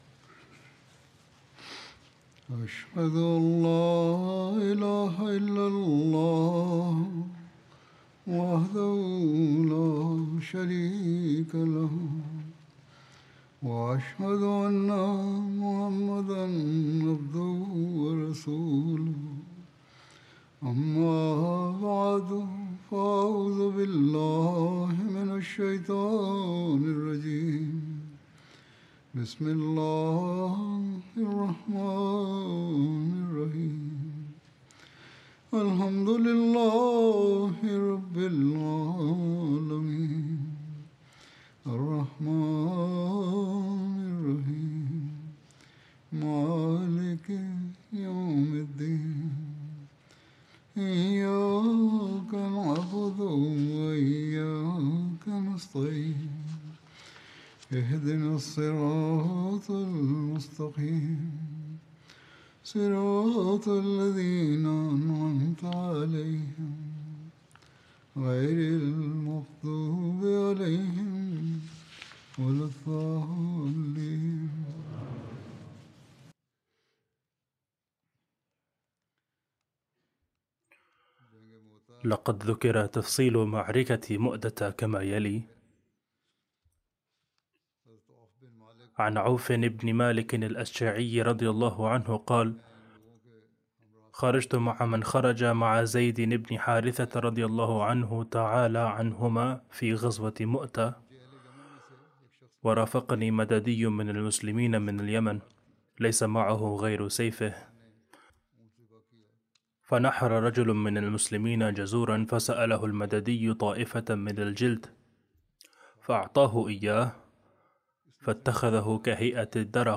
Arabic Translation of Friday Sermon delivered by Khalifatul Masih